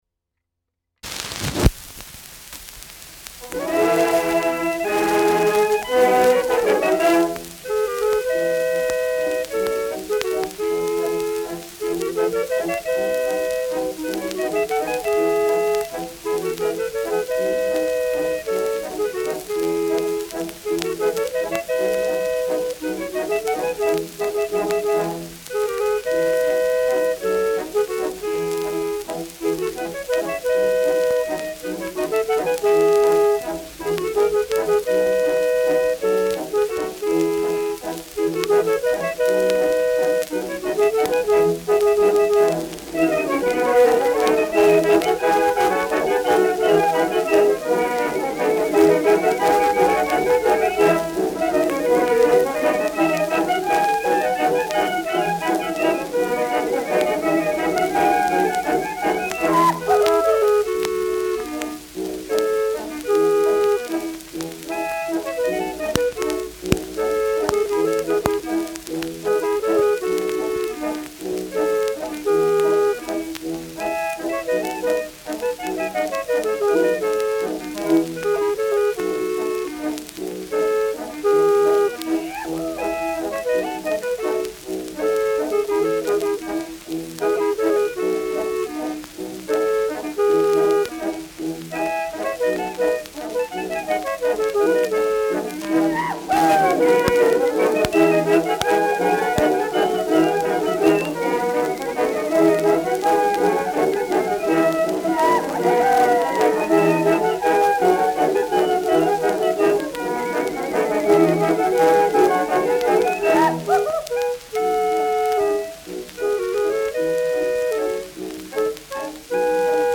Schellackplatte
Stärkeres Grundrauschen : Durchgehend leichtes bis stärkeres Knacken : Leichtes Leiern
Militärmusik des k.b. 1. Infanterie-Regiments, München (Interpretation)